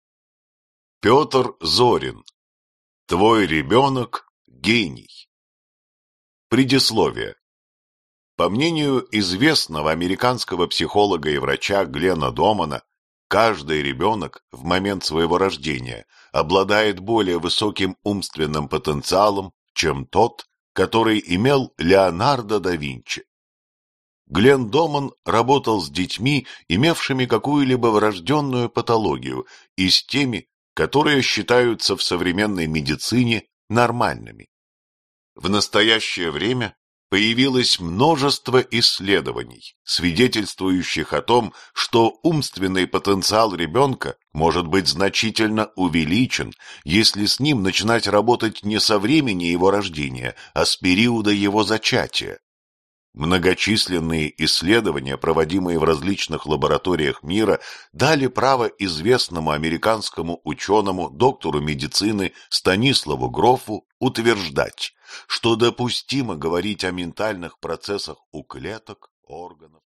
Аудиокнига Твой ребенок – гений | Библиотека аудиокниг